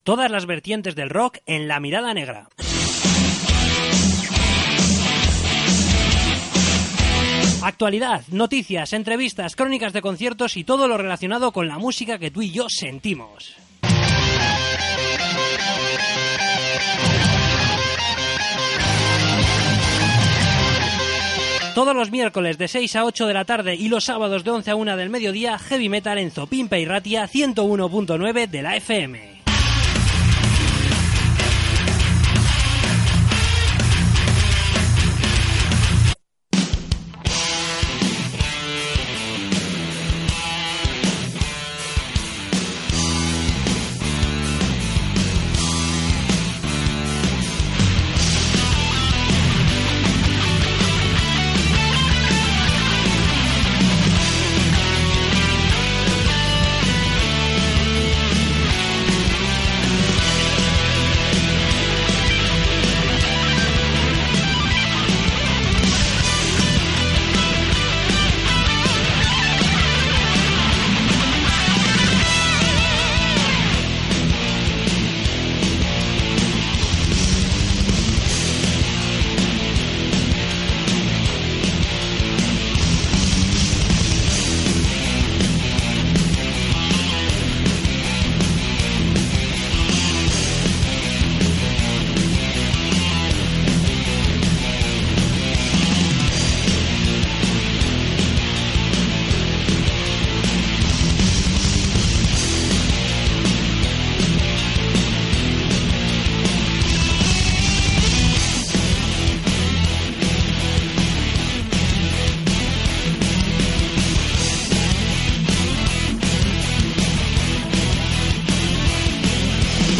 Entrevista con Jardín Infierno
Acústico de Jardin Infierno